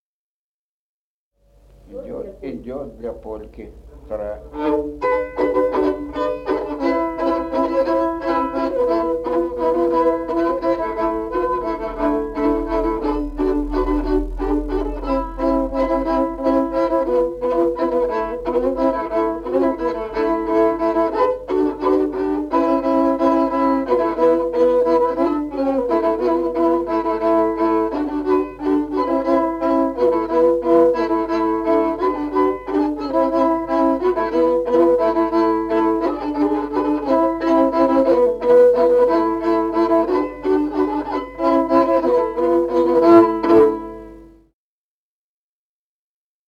Музыкальный фольклор села Мишковка «Полька», партия 2-й скрипки.